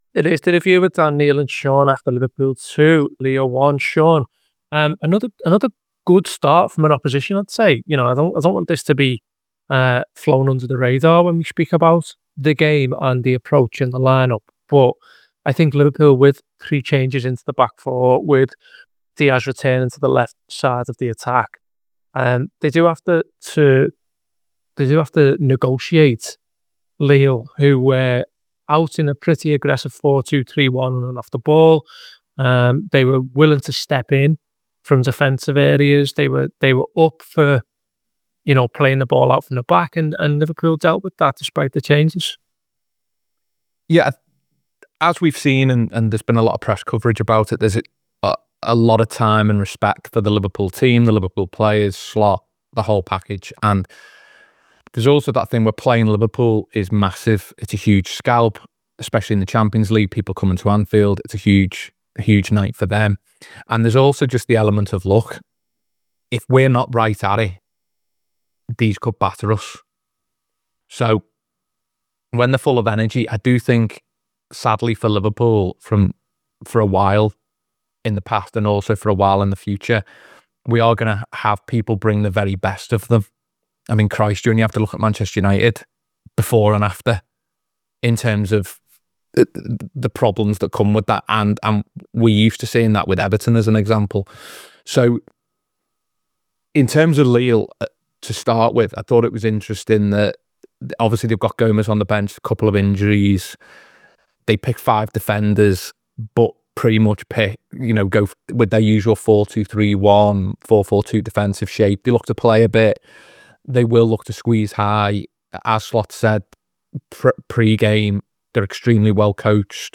Below is a clip from the show- subscribe for more review chat around Liverpool 2 Lille 1…